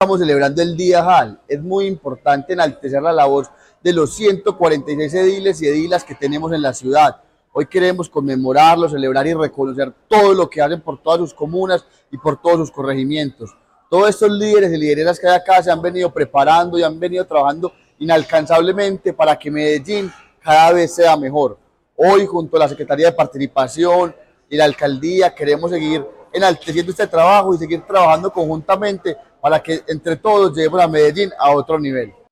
Palabras de Camilo Andrés Cano Montoya, secretario de Participación Ciudadana
Este martes, el Distrito celebró el Día de las Juntas Administradoras Locales (JAL), un espacio que resalta el compromiso y la labor de los ediles y edilas en las 16 comunas y cinco corregimientos de la ciudad.